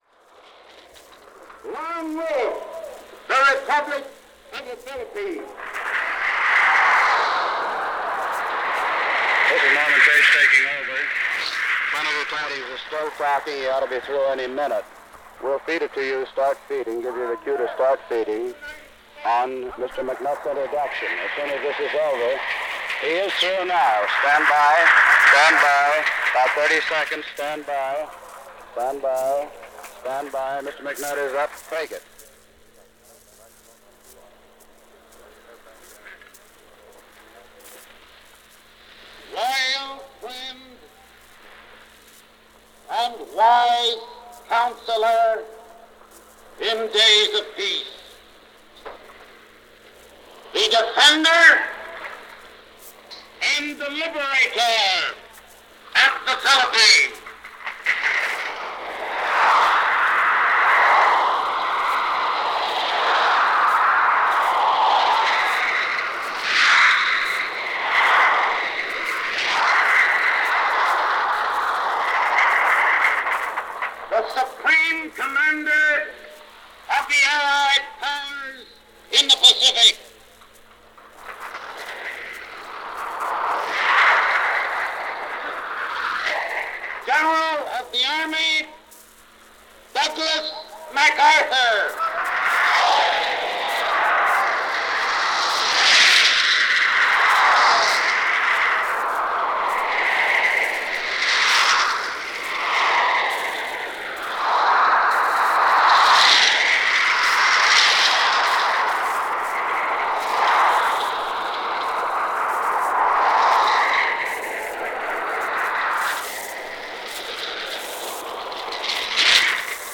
July 25, 1945 - General MacArthur Addresses 1st Congress of the Commonwealth of the Philippines - Past Daily Reference Room
Shortwave report